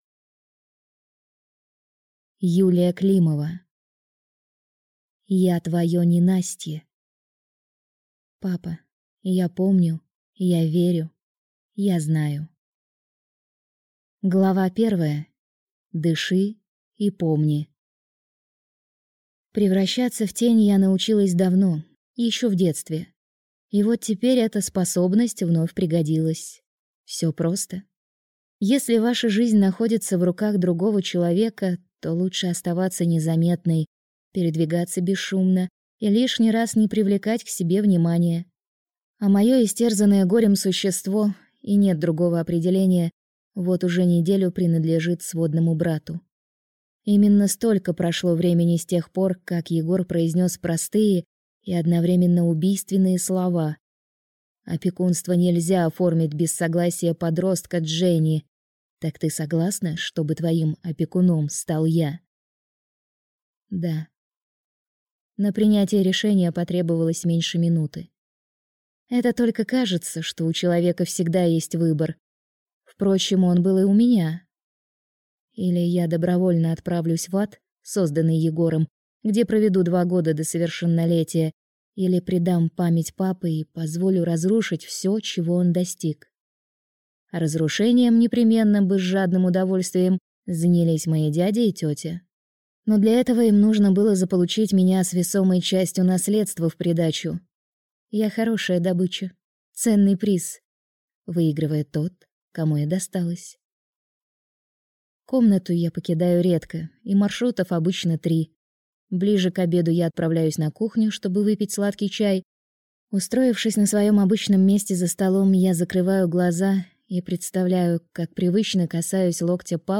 Аудиокнига Я твое ненастье | Библиотека аудиокниг
Прослушать и бесплатно скачать фрагмент аудиокниги